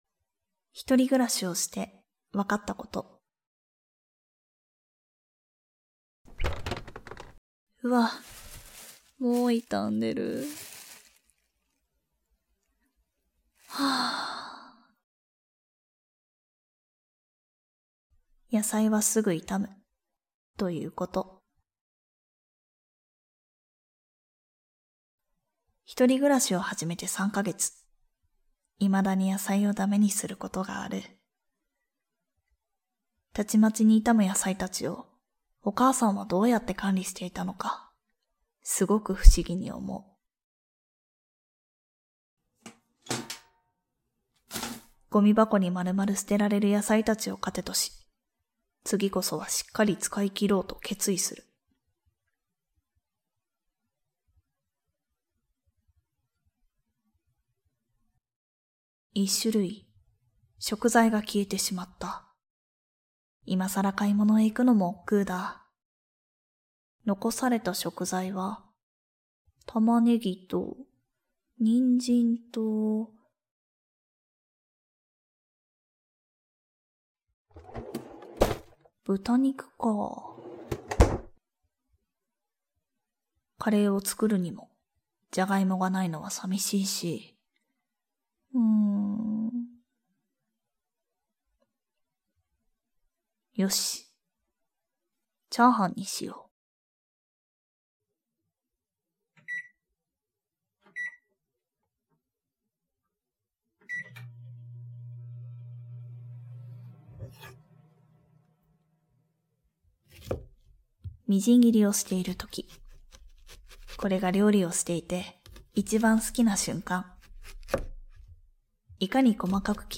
ボイスドラマ「ひとりしずか」 – Podcast
環境音をしっかり乗せたリアルな作風で、臨場感を大切にしています。